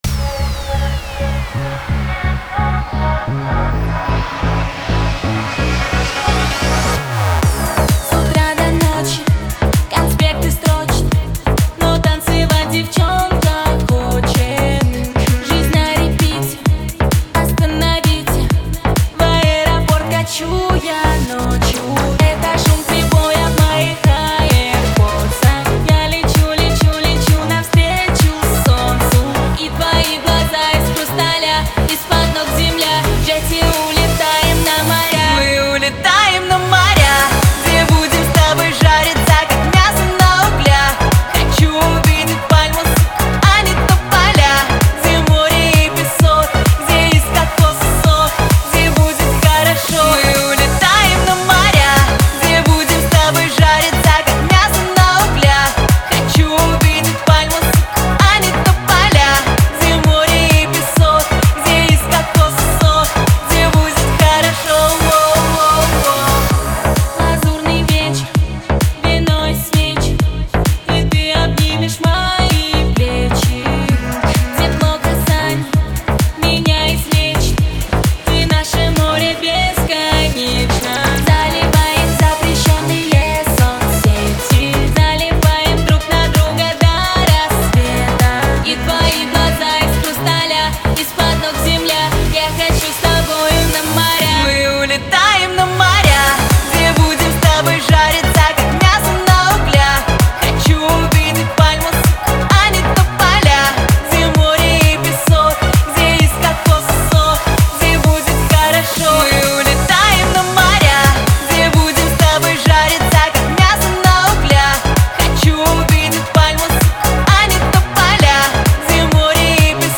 pop , моря , эстрада , Веселая музыка , ансамбль , дуэт